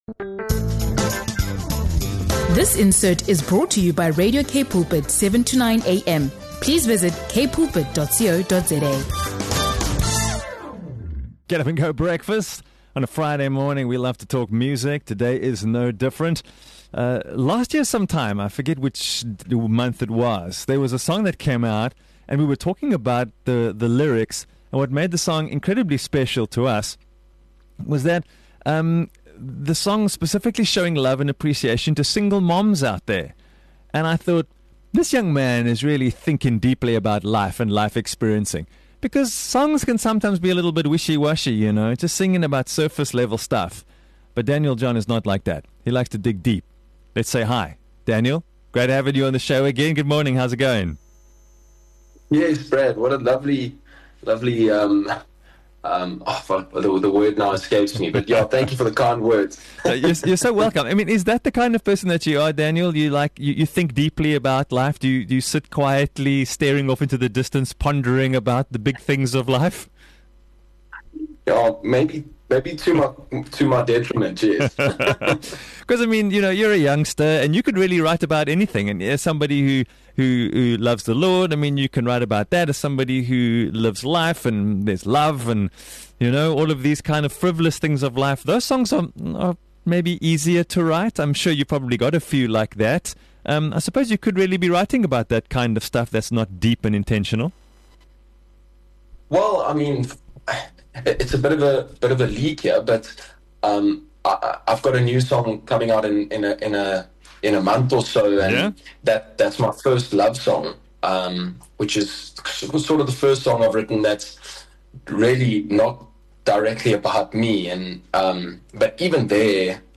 Whether you’re a music lover, seeking inspiration, or exploring your own personal journey, this episode is full of wisdom, encouragement, and deep conversation.